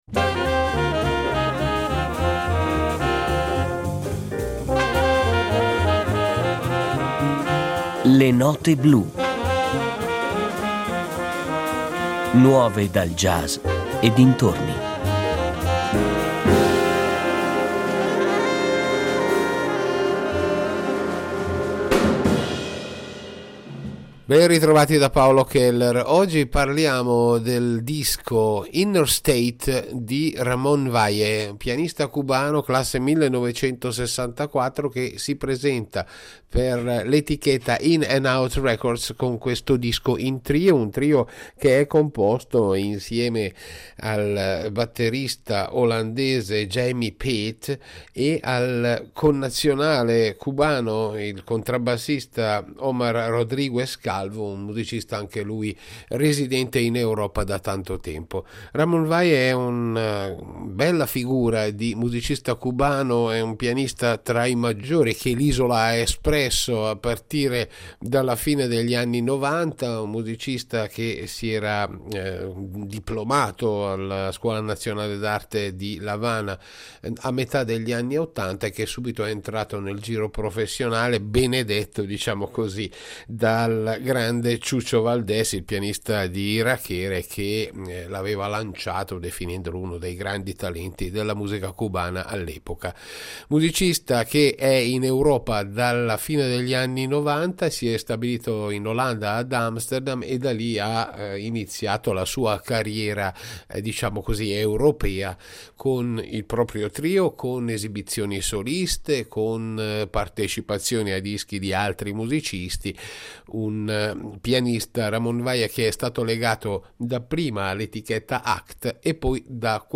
pianista cubano
un album inciso in trio dal vivo a Kassel e a Vienna
al contrabbasso
alla batteria.